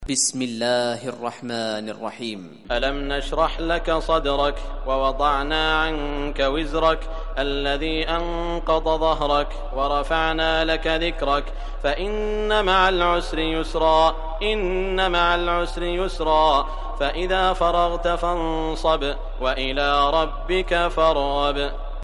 Surah Ash-Sharh Recitation by Sheikh Shuraim
Surah Ash-Sharh, listen or play online mp3 tilawat / recitation in Arabic in the beautiful voice of Sheikh Saud al Shuraim.